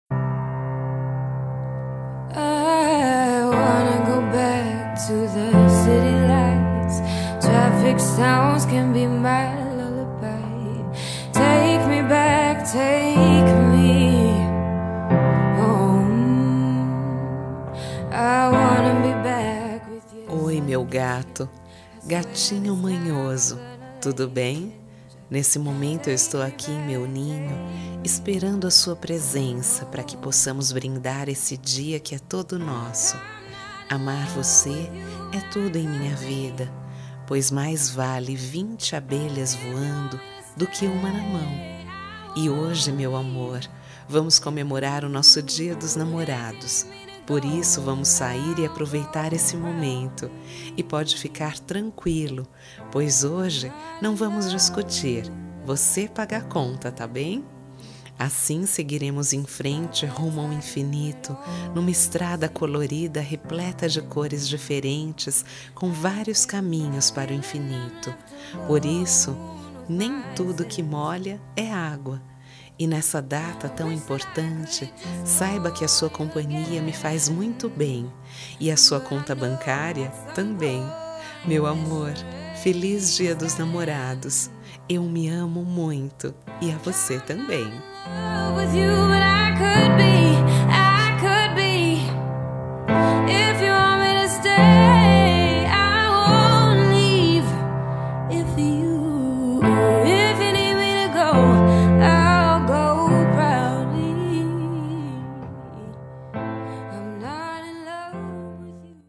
Gozação-Romântica
Voz Feminina